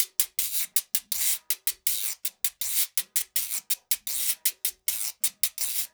80 GUIRO 3.wav